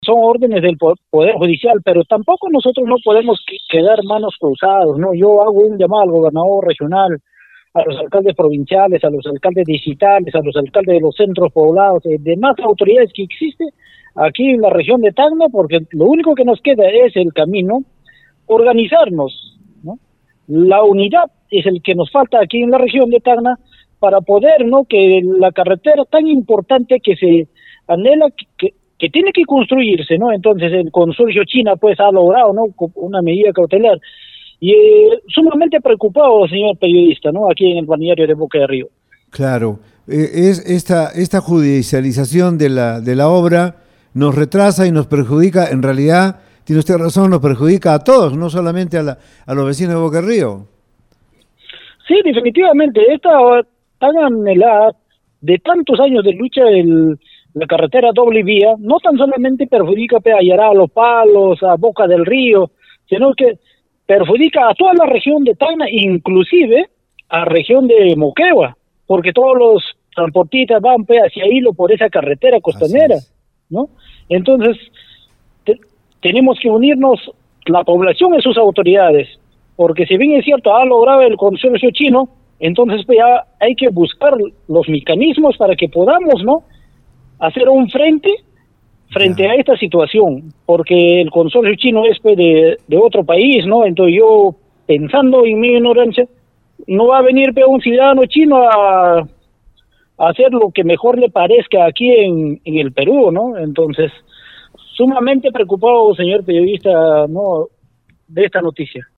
En declaraciones a Radio Uno, el alcalde indicó que esta judicialización no solo «nos retrasa», sino que perjudica gravemente a toda la región de Tacna, e incluso a la región de Moquegua.
3juan-mamani-alcalde-boca-del-rio.mp3